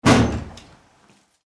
Pegarle una patada a una puerta
golpear
Sonidos: Acciones humanas